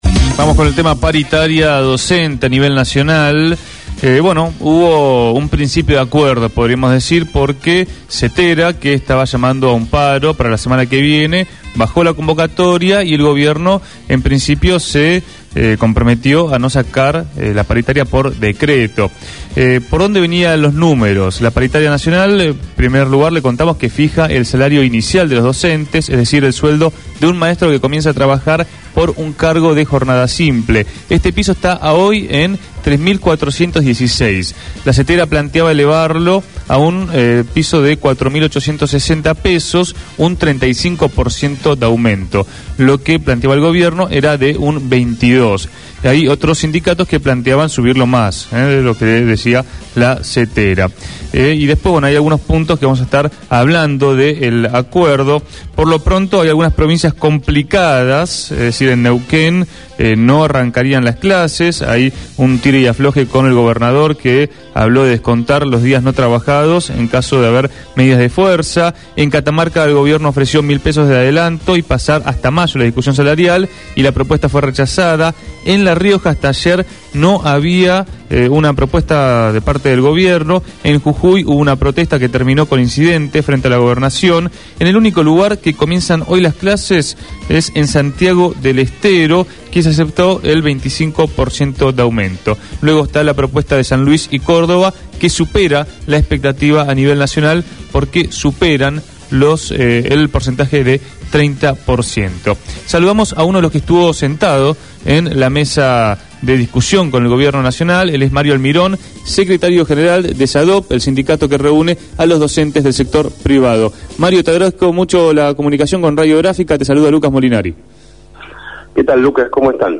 fue entrevistado en Punto de Partida. Opinó sobre las reuniones que vienen manteniendo con el gobierno nacional por las paritarias docentes.